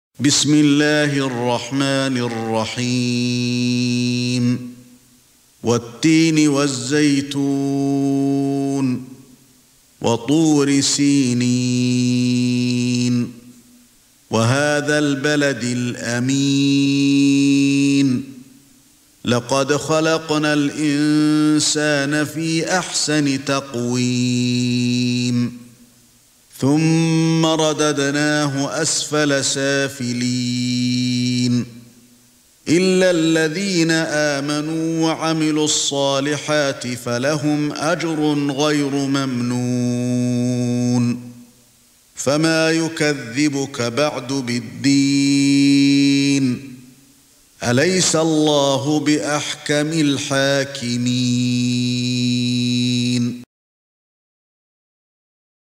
سورة التين ( برواية قالون ) > مصحف الشيخ علي الحذيفي ( رواية قالون ) > المصحف - تلاوات الحرمين